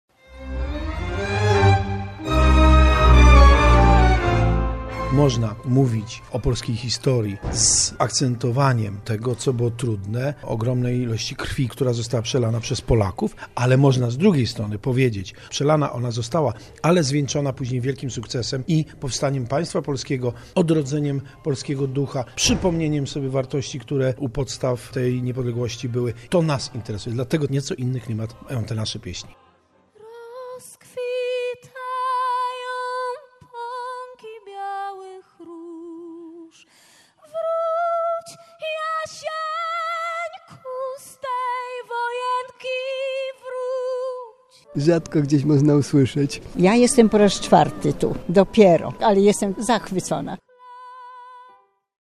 Koncert w Świątyni Opatrzności Bożej
Z okazji 101. rocznicy odzyskania przez Polskę niepodległości w Świątyni Opatrzności Bożej odbył się koncert „Boże, coś Polskę”. Śpiewano polskie pieśni religijne i patriotyczne.
Ostatnią część koncertu stanowiło wspólne śpiewanie pieśni patriotycznych.